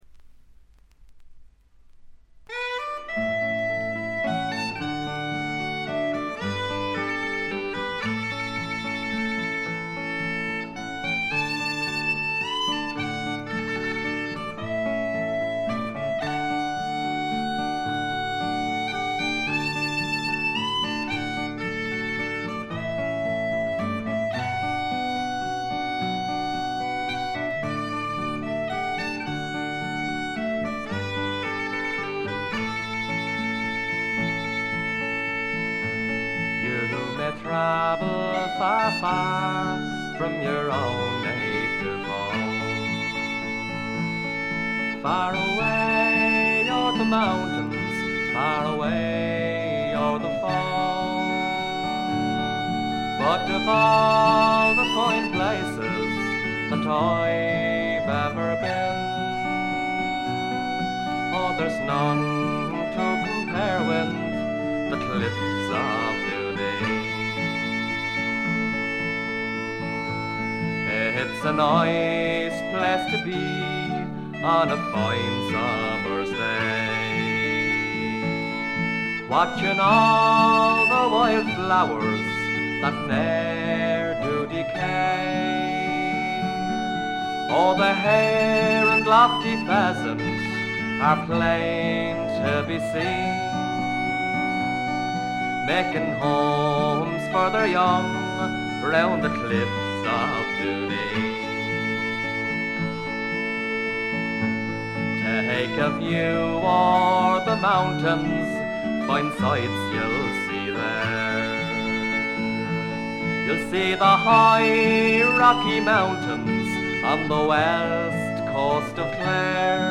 中身は哀切なヴォイスが切々と迫る名盤。
試聴曲は現品からの取り込み音源です。